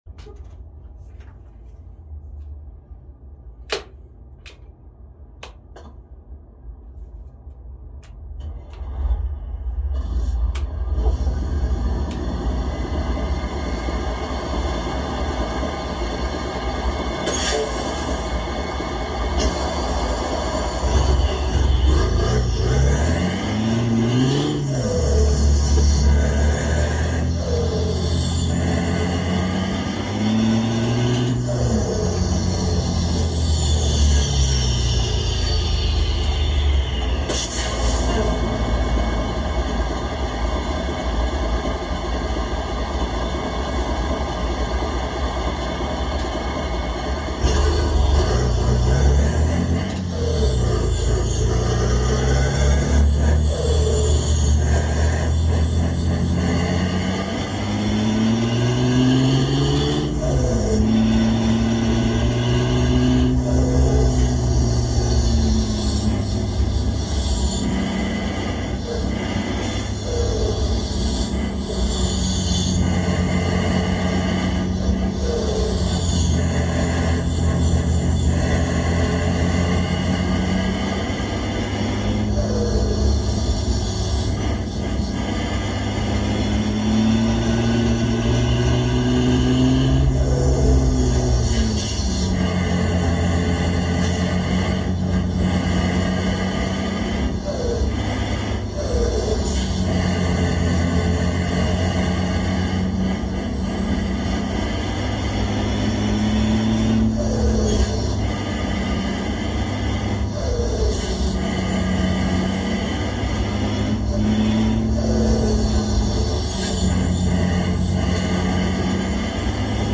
Scania V8 open pipe